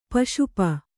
♪ paśu pa